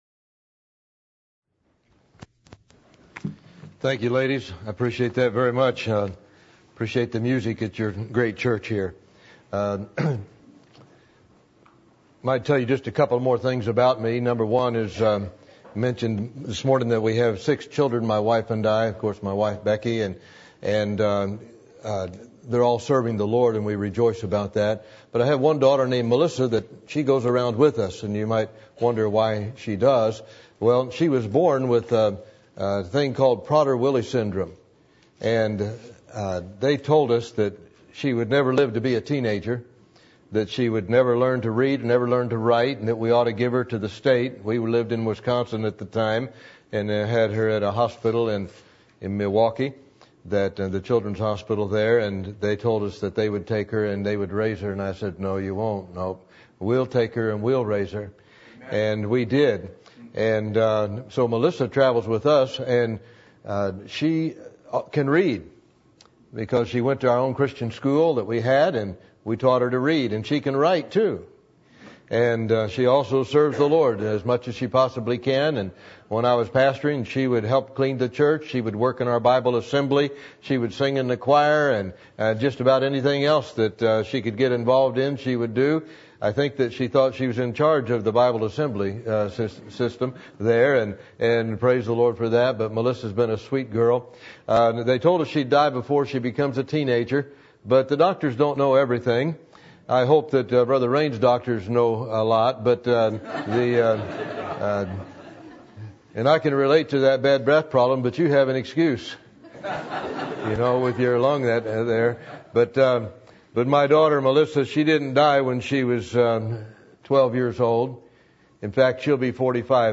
Passage: John 4:4 Service Type: Sunday Evening %todo_render% « Are You Going To Heaven When You Die?